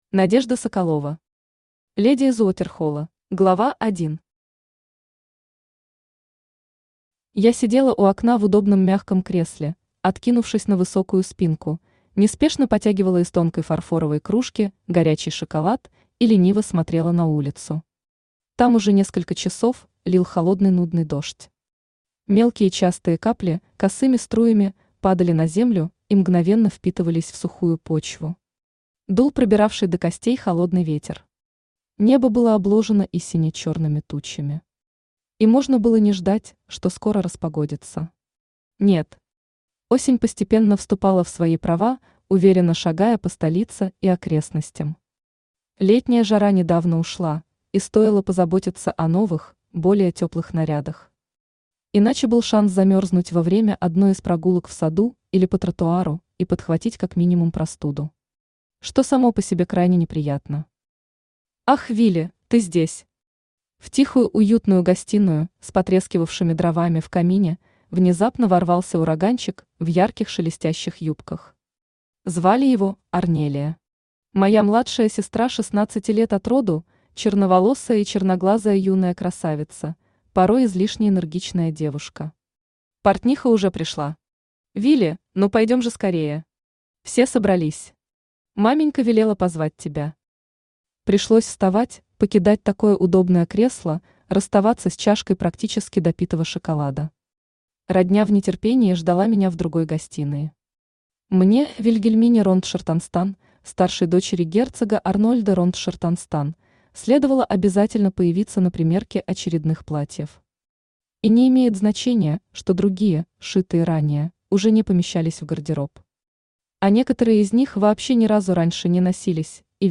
Аудиокнига Леди из Уотерхолла | Библиотека аудиокниг
Aудиокнига Леди из Уотерхолла Автор Надежда Игоревна Соколова Читает аудиокнигу Авточтец ЛитРес.